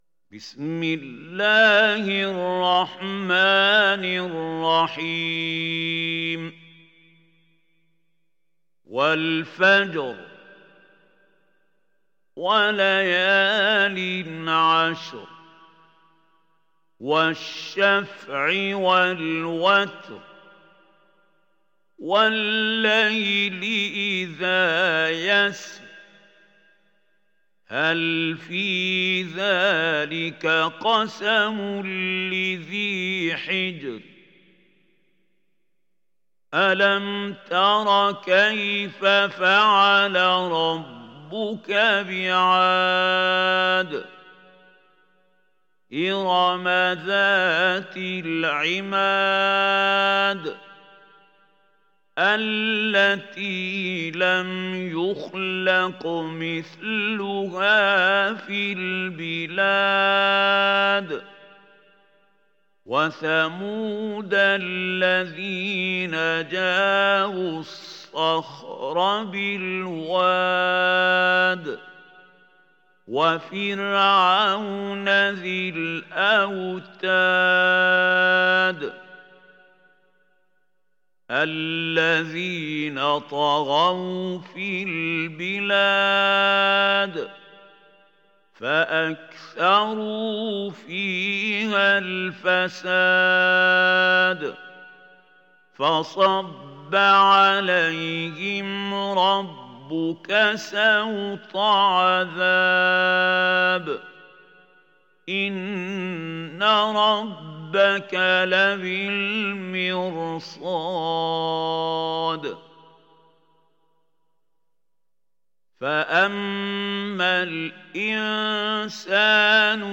Surat Al Fajr Download mp3 Mahmoud Khalil Al Hussary Riwayat Hafs dari Asim, Download Quran dan mendengarkan mp3 tautan langsung penuh